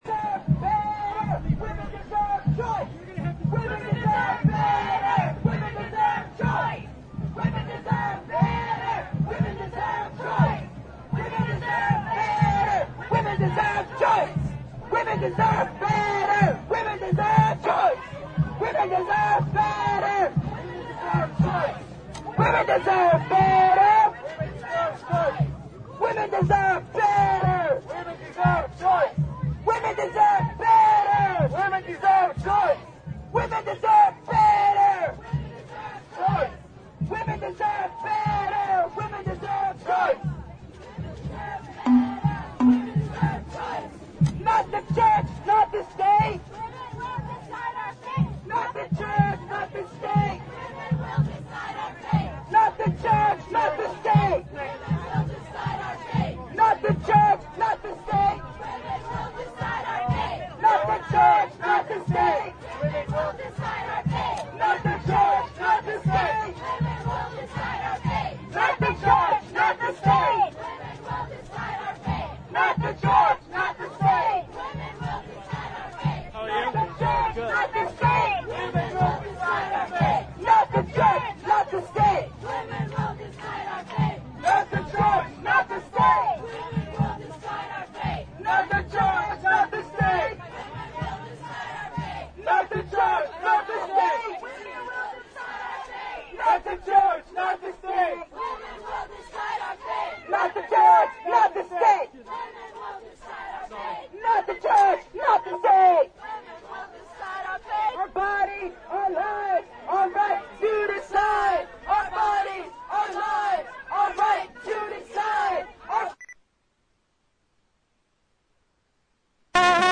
§Pro-choice street march chants
Hear spirited chants as pro-choice activists follow the Walk for Life March and let them know SF is a pro-choice town they are not welcome in.
walk08echantsedit2.mp3